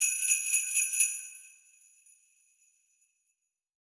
Sleigh Bells (3).wav